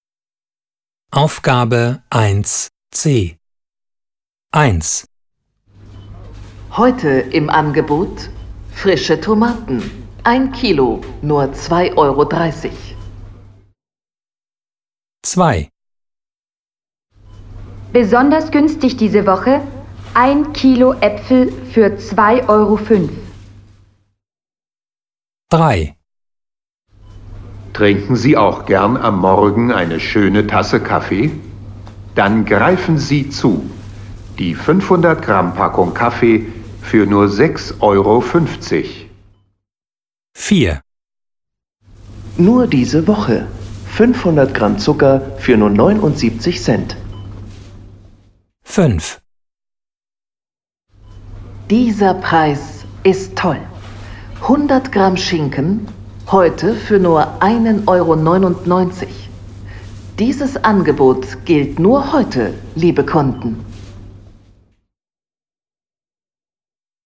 Sie hören jeden Text zweimal.